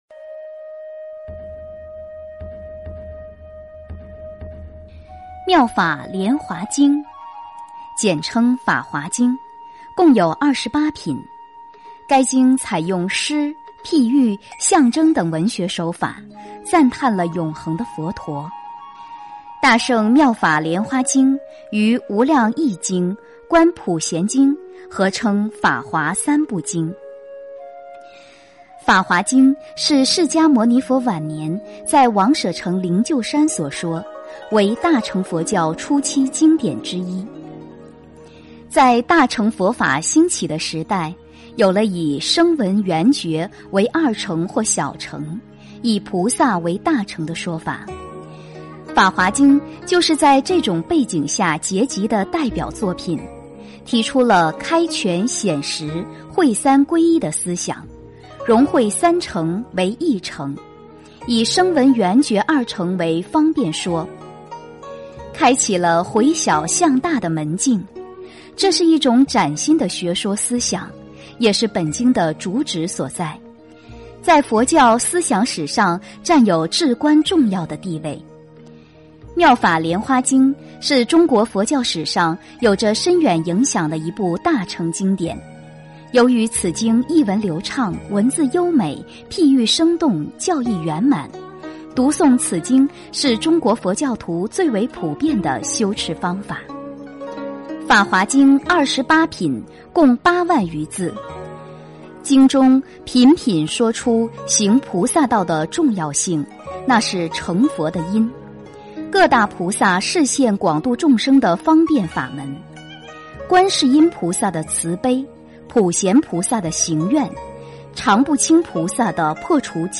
《妙法莲华经》序品第一 诵经 《妙法莲华经》序品第一--佚名 点我： 标签: 佛音 诵经 佛教音乐 返回列表 上一篇： 心经(天籁梵音) 下一篇： 《妙法莲华经》信解品第四 相关文章 灵启--黛青塔娜&HAYA乐团 灵启--黛青塔娜&HAYA乐团...